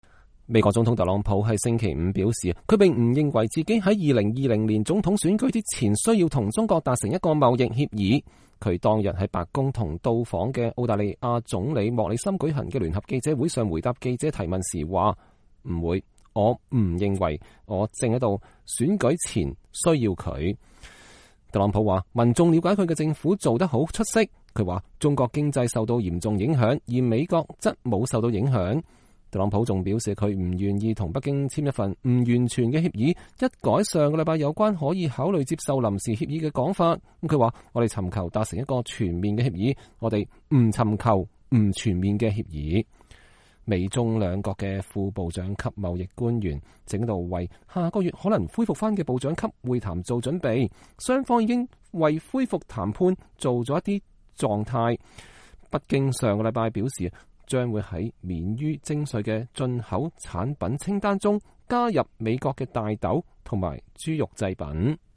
特朗普總統星期五（9月20日）在白宮會晤到訪的澳大利亞總理莫里森期間發表講話。